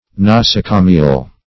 Nosocomial \Nos`o*co"mi*al\ (n[o^]s`[-o]*k[=o]"m[i^]*al), a. [L.